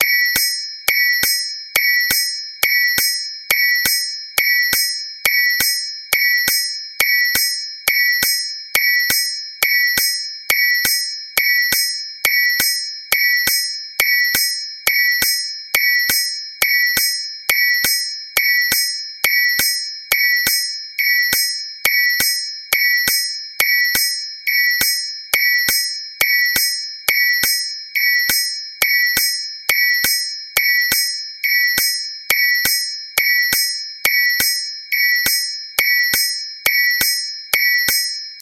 熱く熱せられた鉄を何度もひたすら叩くことで強度を上げます。